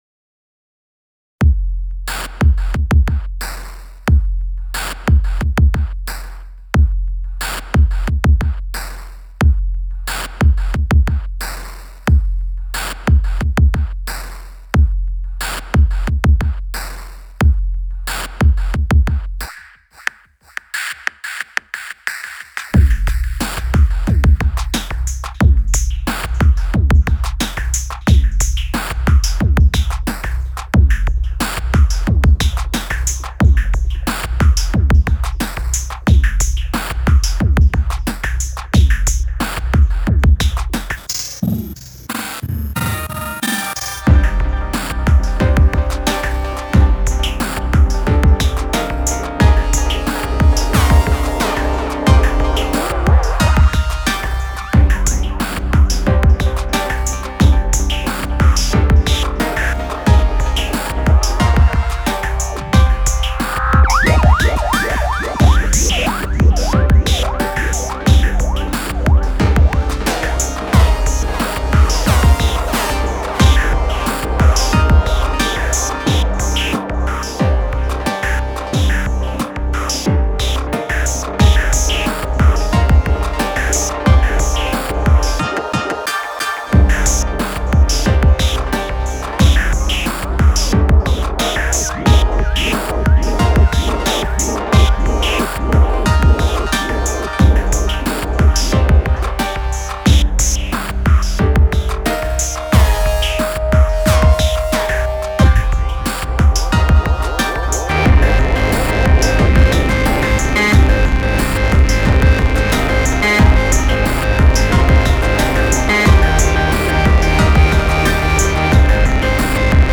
Analog Four MkII, Digitakt II and the Octatrack with A4 performance macro shenanigans targeting its delay parameters on the FX track.